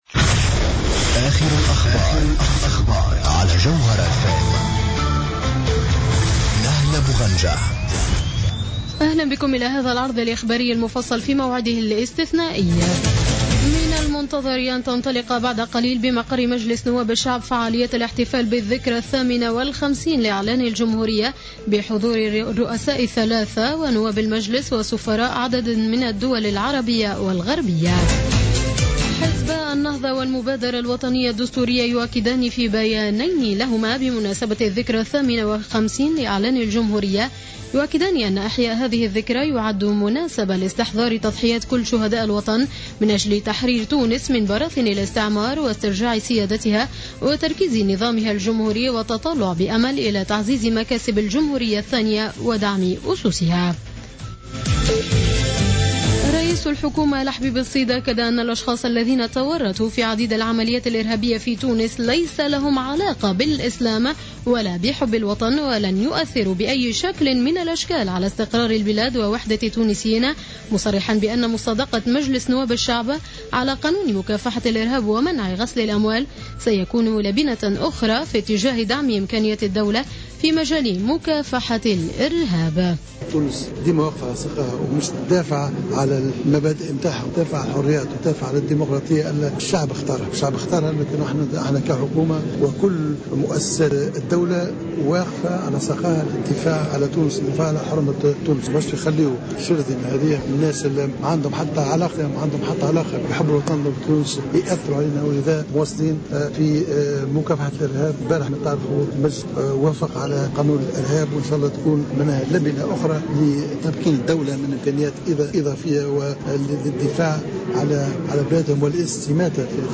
نشرة أخبار السابعة مساء ليوم السبت 25 جويلية 2015